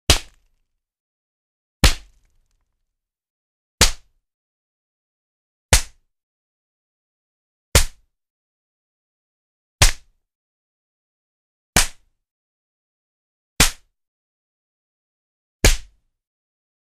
На этой странице собраны разнообразные звуки шлепков — от легких хлопков до сочных ударов.
Разные варианты звуков пощечин для монтажа